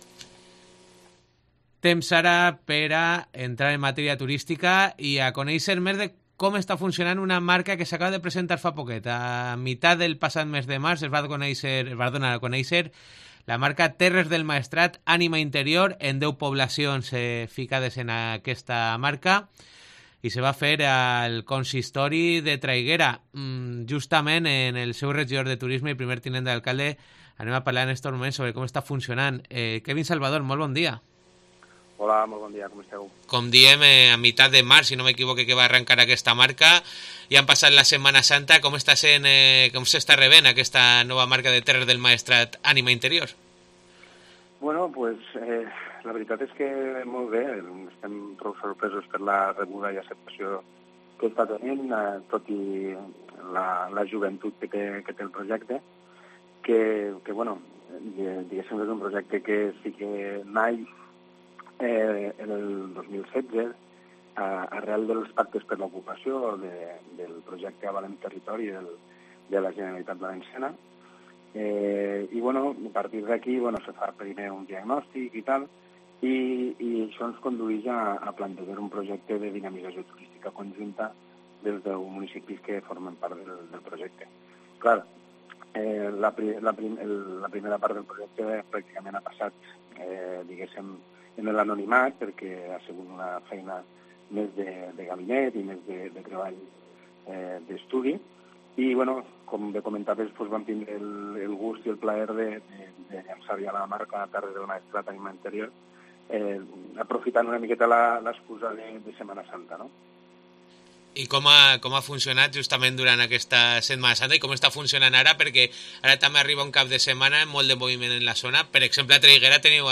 Entrevista a Kevin Salvador (Terres del Maestrat)
Hem parlat a "Herrera en COPE" amb el regidor de Turisme de Traiguera, Kevin Salvador, que ens ha presentat la nova marca turística Terres del Maestrat.